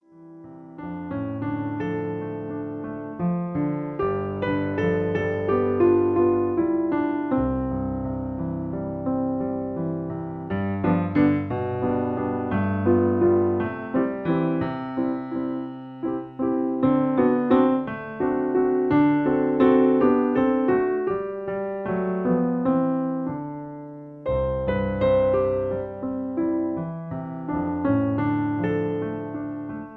In C. Piano Accompaniment